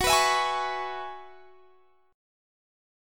F#9sus4 Chord
Listen to F#9sus4 strummed